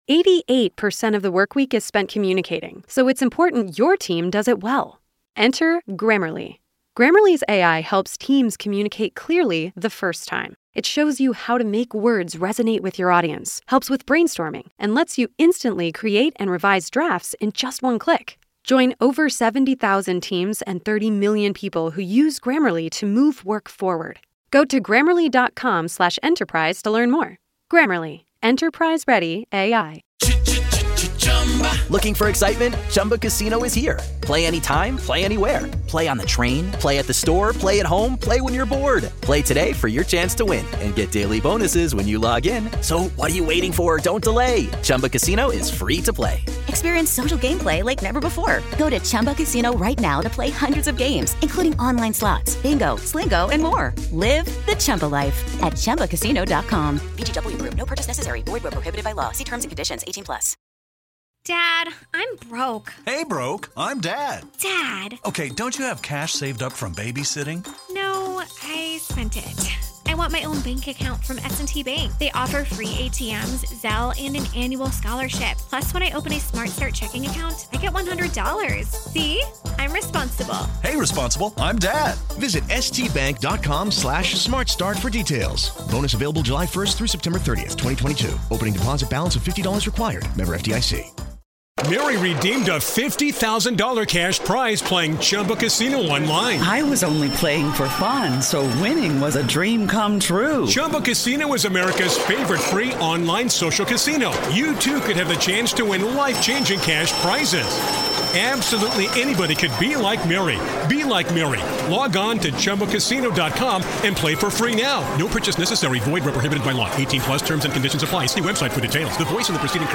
USB Lavalier Lapel Microphone